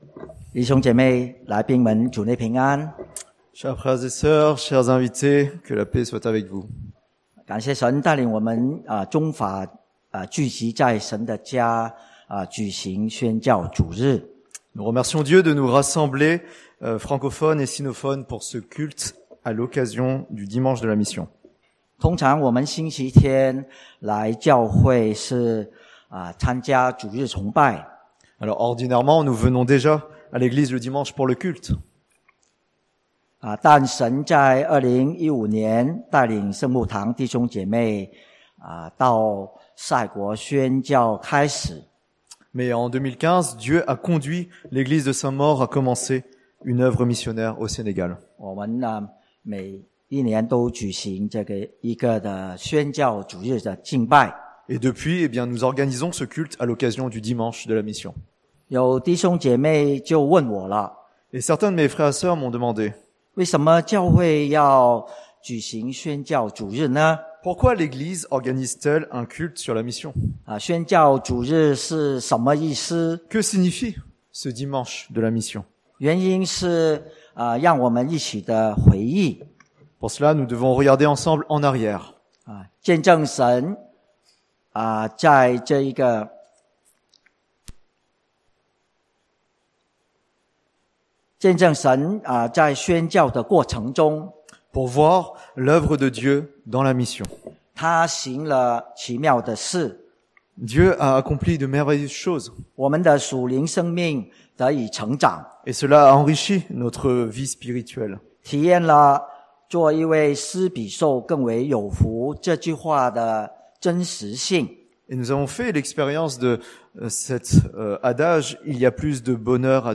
chinois traduit en français